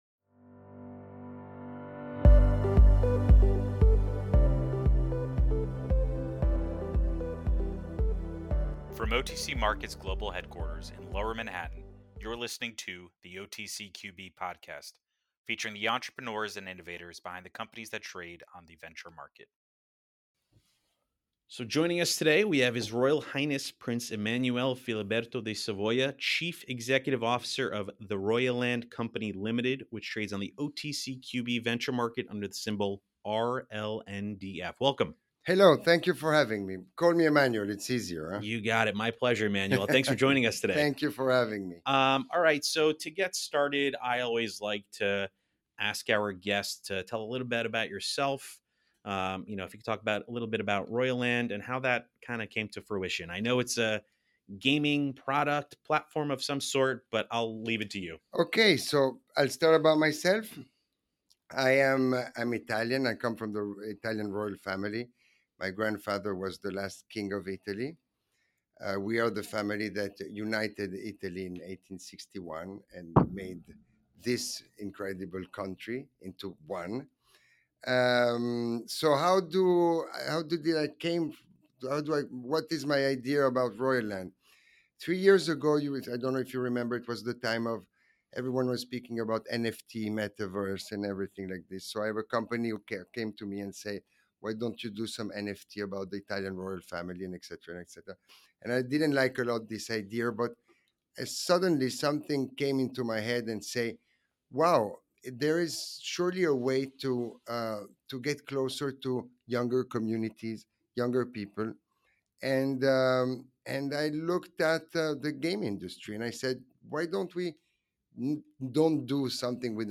Interview with His Royal Highness Prince Emanuele Filiberto di Savoia